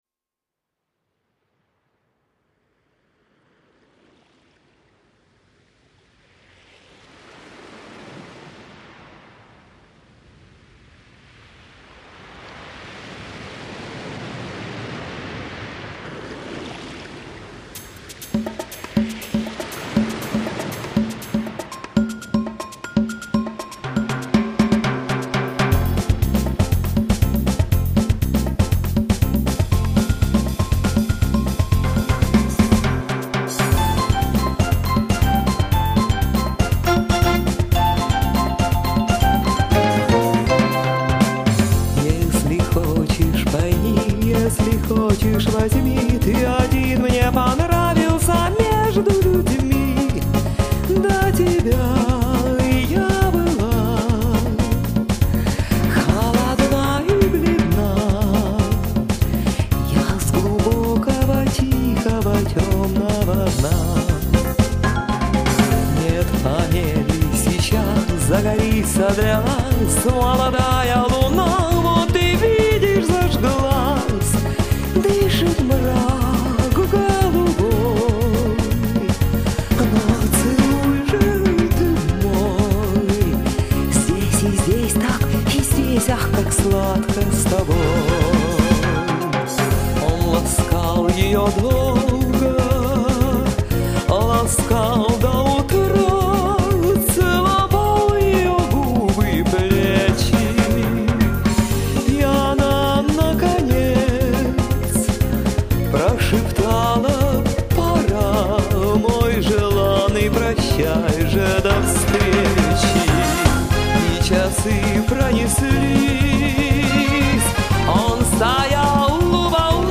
Сказочность таится в ее шероховато-тающем, проникновенном голосе, интонациях, в тÓмной глубине грудных предыханий.
Мы же очевидцы хорошего импульса в развитии славянского шансона, который прекрасно продлевает золотые традиции  песенной культуры.